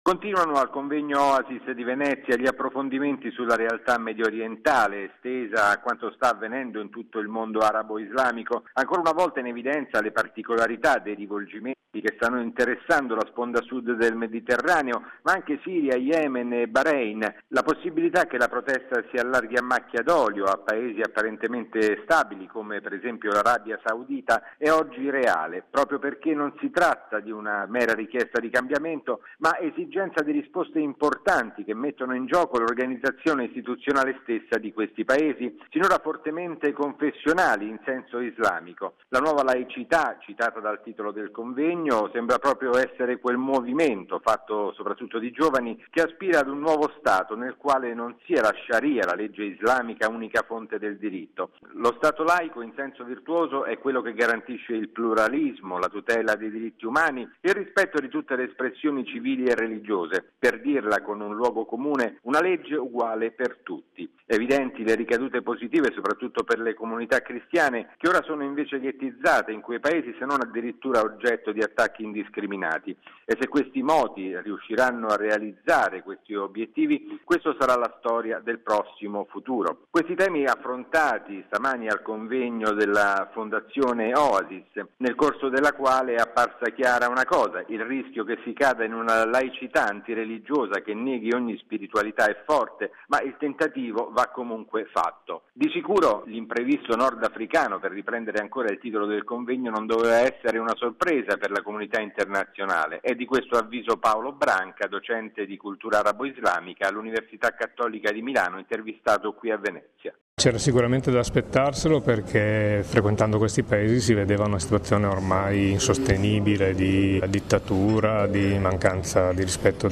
◊   Seconda giornata di lavori a Venezia, al Convegno promosso dalla Fondazione Oasis sul tema, quanto mai attuale, "Medio Oriente verso dove? Nuova laicità e imprevisto nordafricano".